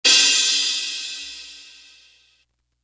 Crashes & Cymbals
Montezuma Crash.wav